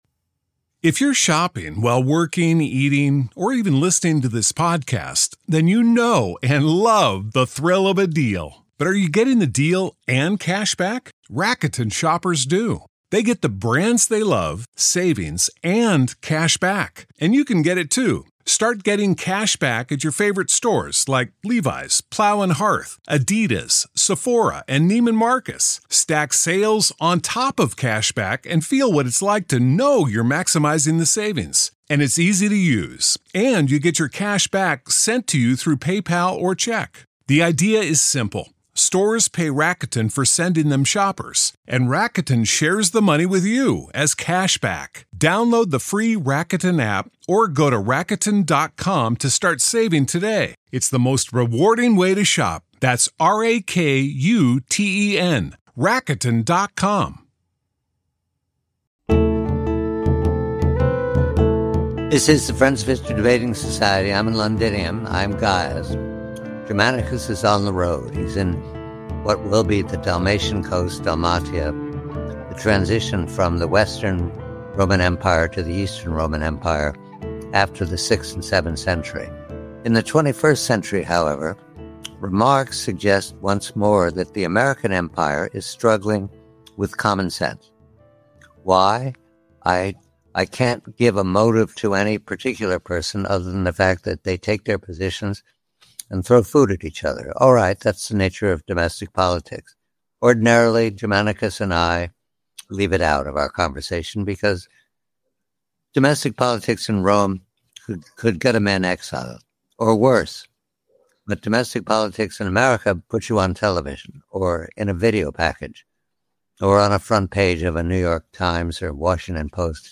Ruling Elite vs. Populism and the Threat of Revolution The speakers analyze domestic American conflict, using David Brooks's framework of the credentialed elite versus the non-credentialed public.